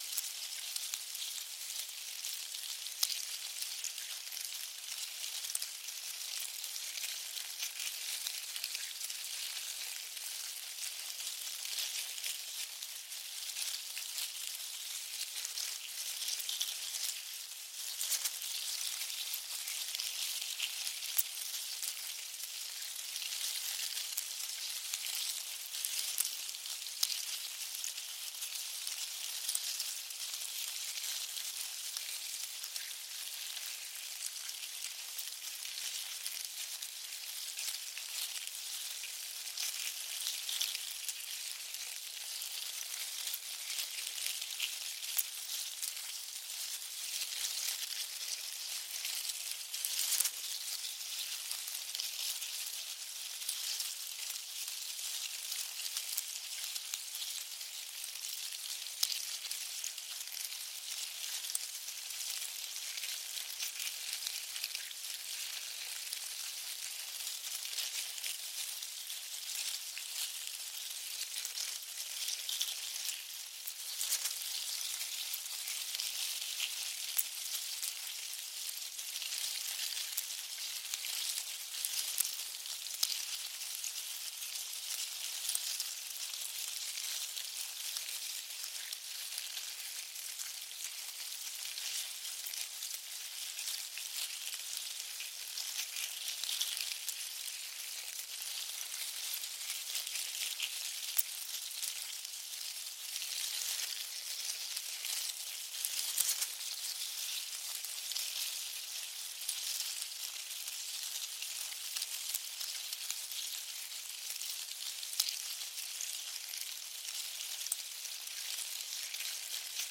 На этой странице собраны звуки термитов – от тихого шуршания до характерного постукивания.
Звуки термитника (жизнь колонии термитов)